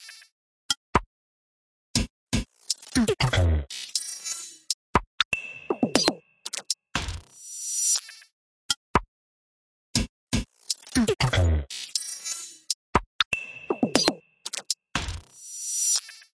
标签： glitchy dance groovy
声道立体声